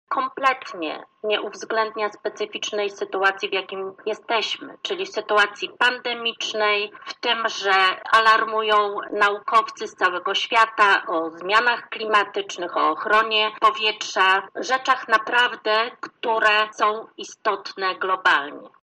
Ten plan został stworzony z oderwaniem od rzeczywistości – mówi radna Bożena Lisowska z Platformy Obywatelskiej: